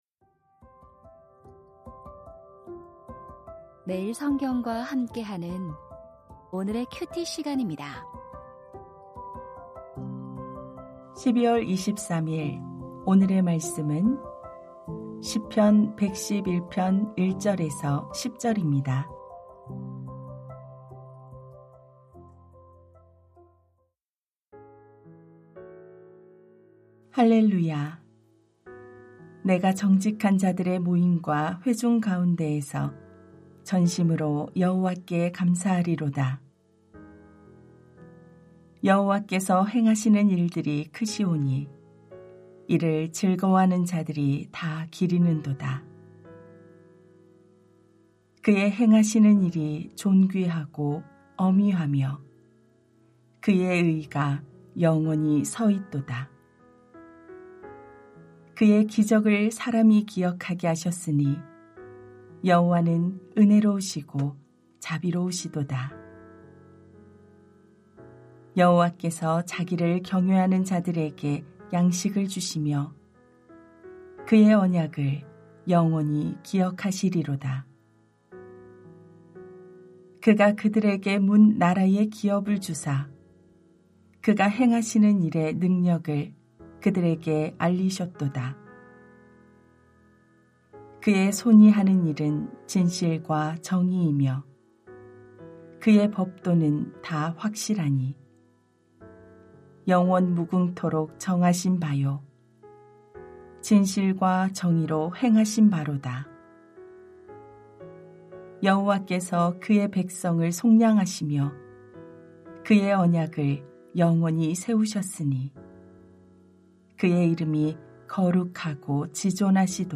시편 111:1-10 참된 지혜의 삶 2024-12-23 (월) > 오디오 새벽설교 말씀 (QT 말씀묵상) | 뉴비전교회